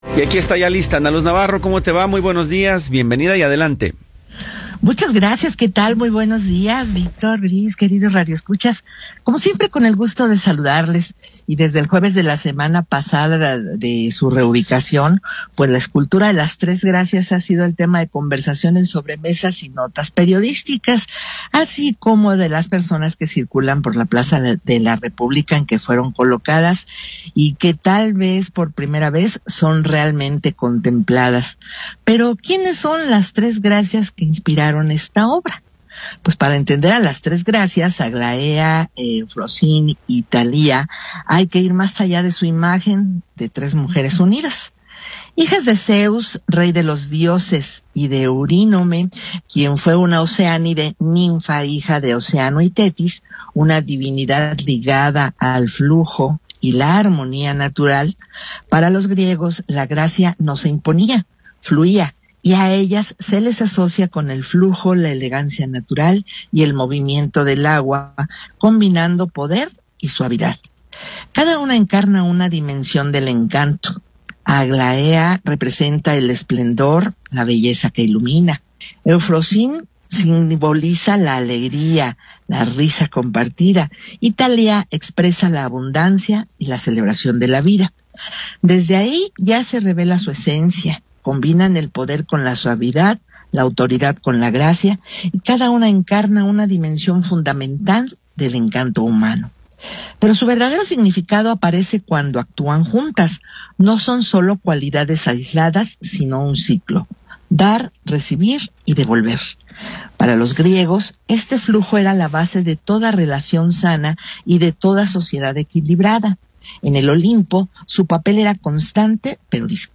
Comentario